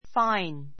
fáin ふァ イン